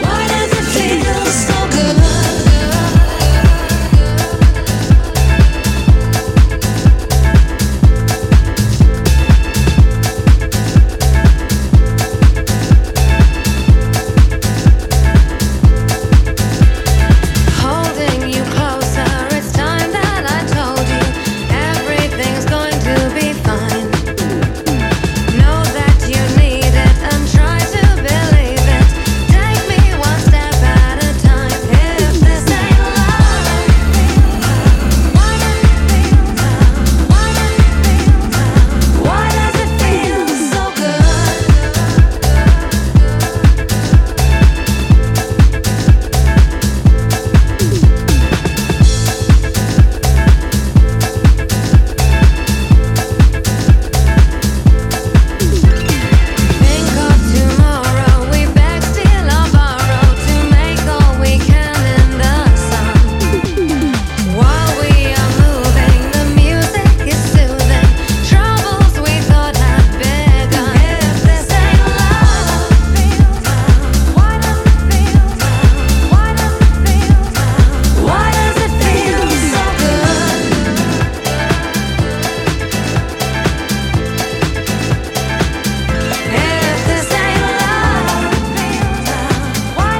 ジャンル(スタイル) DISCO HOUSE / DEEP HOUSE / POP